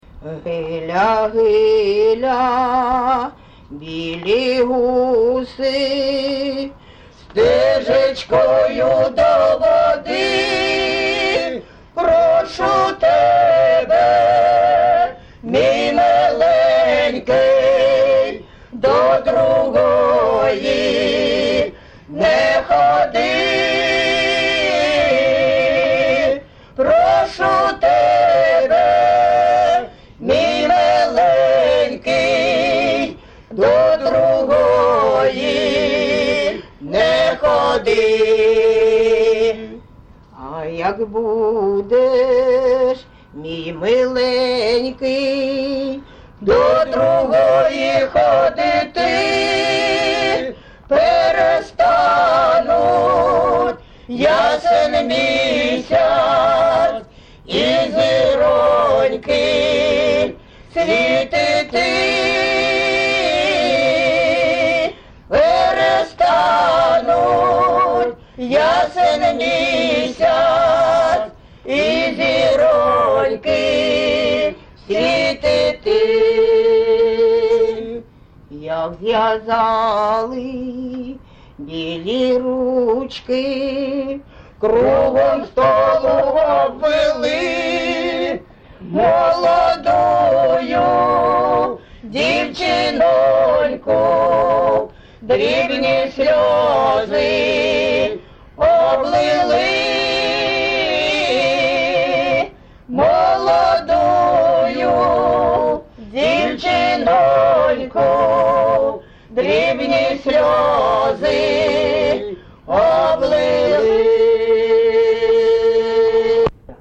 ЖанрПісні з особистого та родинного життя, Романси
Місце записус-ще Красноріченське, Кремінський район, Луганська обл., Україна, Слобожанщина